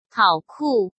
hǎo kù